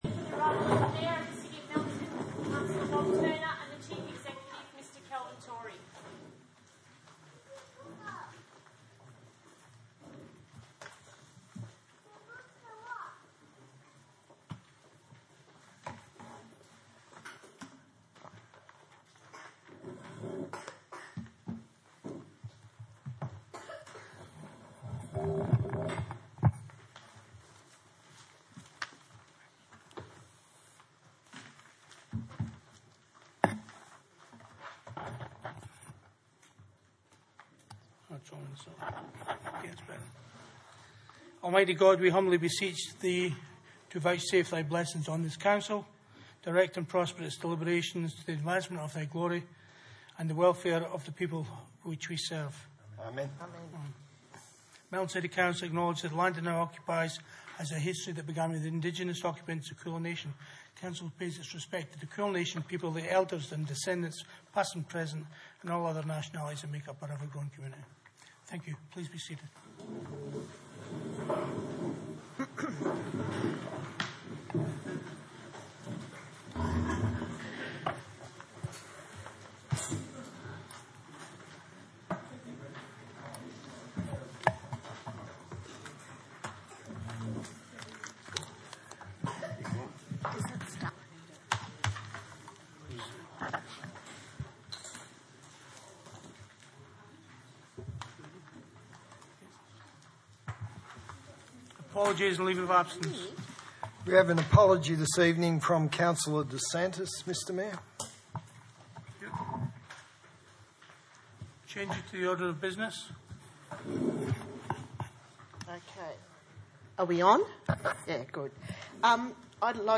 Ordinary Meeting 4 February 2019
Burnside Community Hall, 23 Lexington Drive, Burnside, 3023 View Map